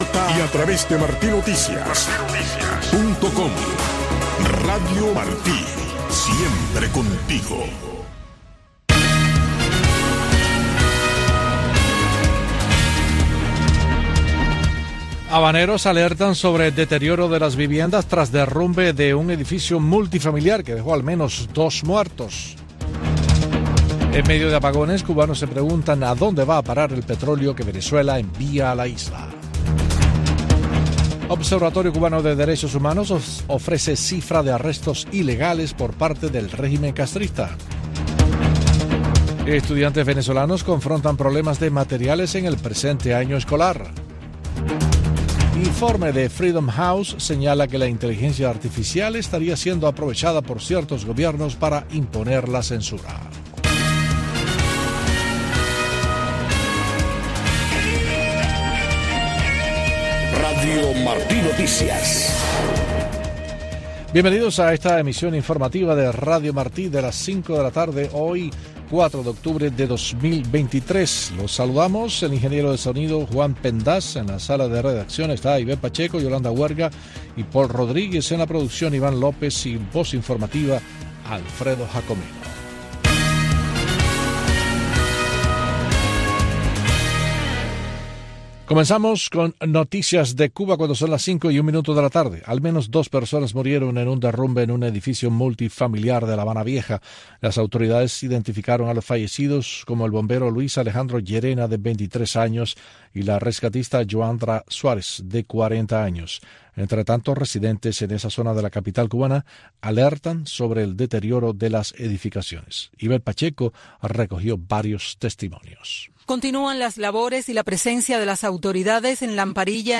Noticiero de Radio Martí 5:00 PM